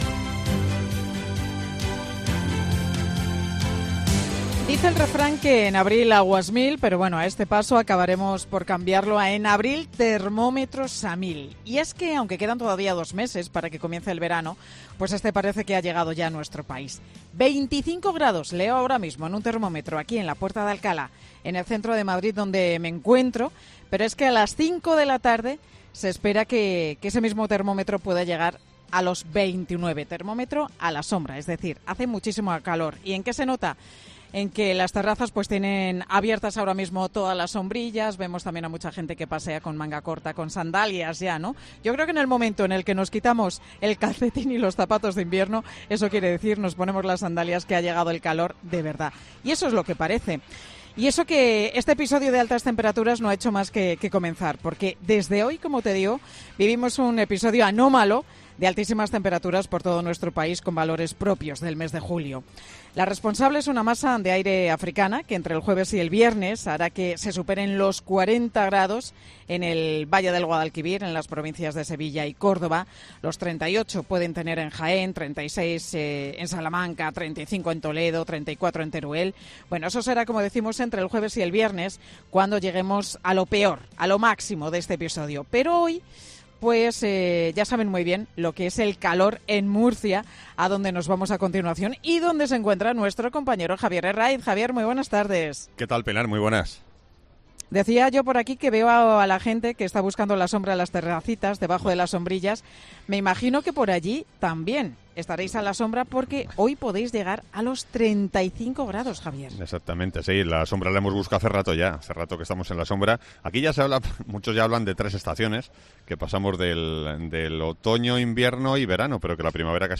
El extremo calor de finales de abril ya es un hecho: Pilar García Muñiz te lo cuenta desde la Puerta de Alcalá